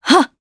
Isolet-Vox_Attack5_jp.wav